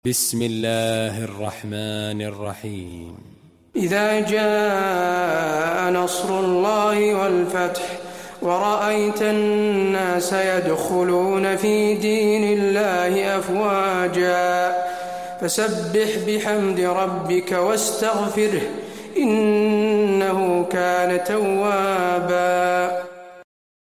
المكان: المسجد النبوي النصر